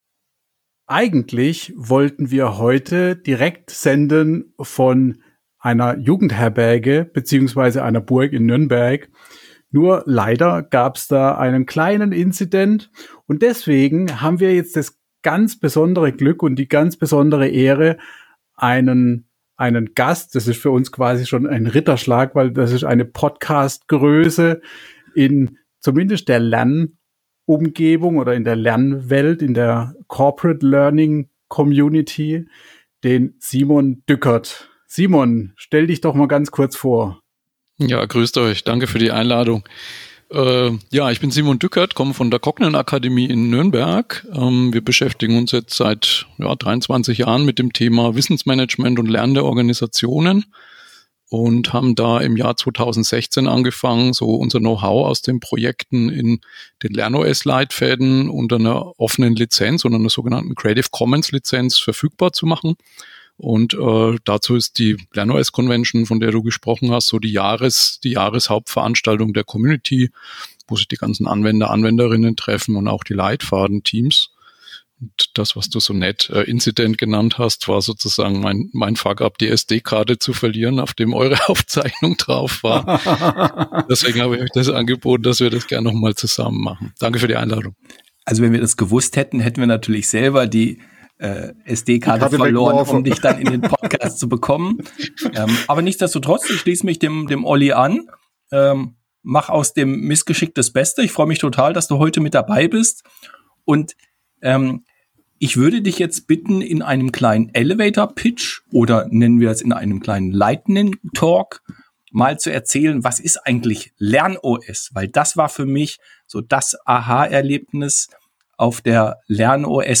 Dann habe ich aber die SD-Karte mit der Aufnahme verloren :-( Daher haben wir die Chance genutzt und die Episode einfach gemeinsam nochmal aufgenommen. Shownotes: Was ist eigentlich lernOS? lernOS unter Creative Commons Lizenz (wie Open Source Lizenzen, aber für Inhalte, nicht Software-Code) Wie funktionioniert das Lernen mit einem lernOS Leitfaden?